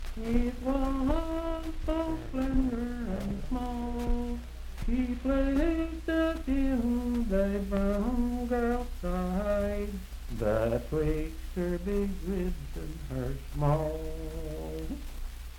Unaccompanied vocal music
in Dryfork, WV
Verse-refrain 1(3).
Voice (sung)
Randolph County (W. Va.)